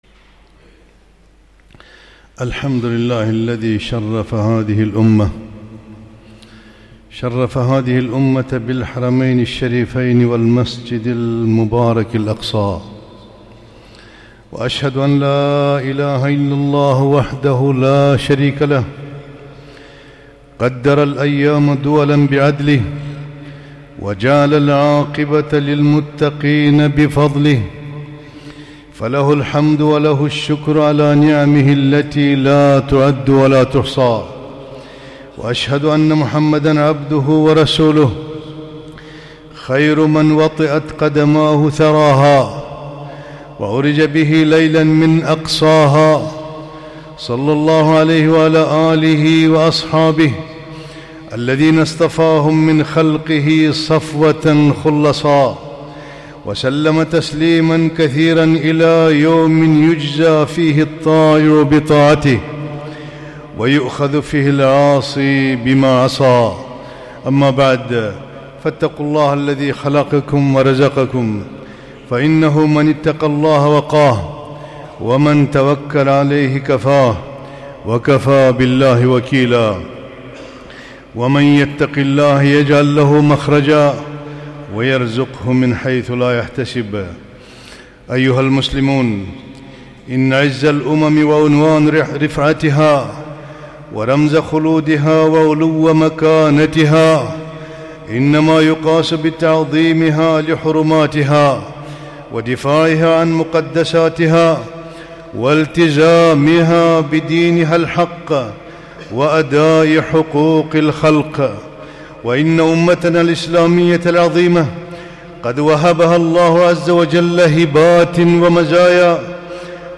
خطبة - إن نصر الله قريب